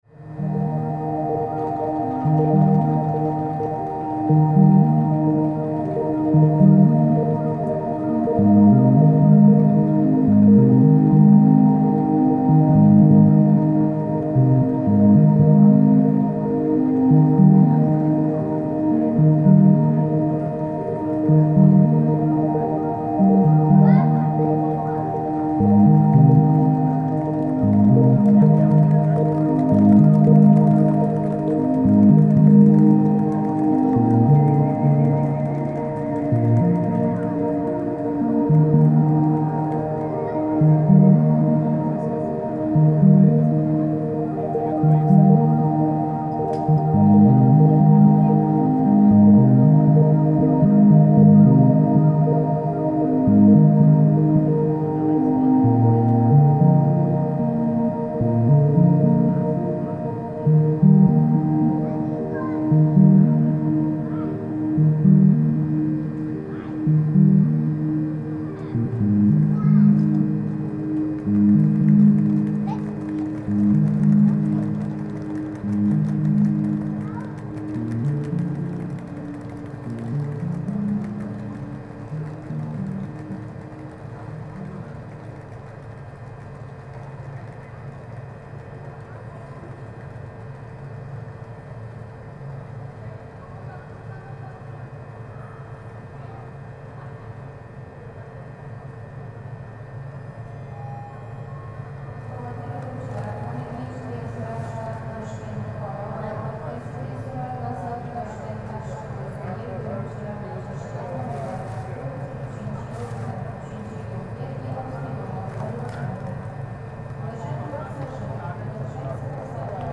What an incredible sense of space and movement in the recording.